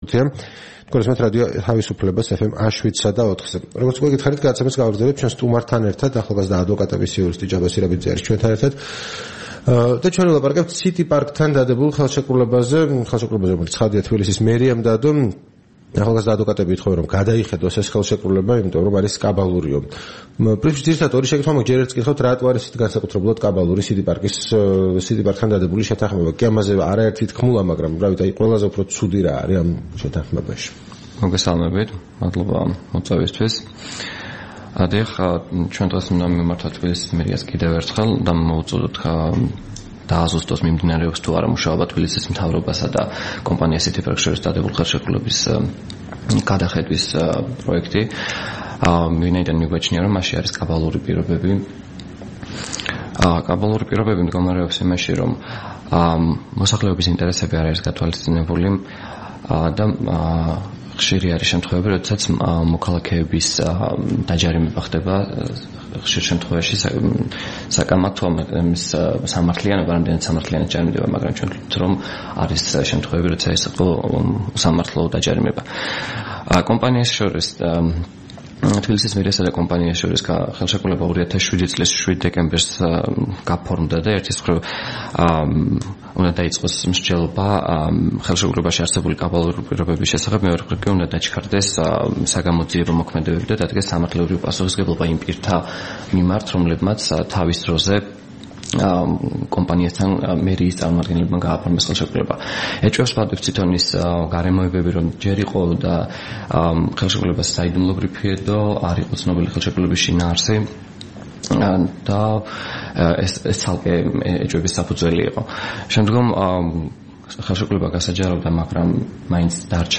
რადიო თავისუფლების თბილისის სტუდიაში სტუმრად იყო
საუბარი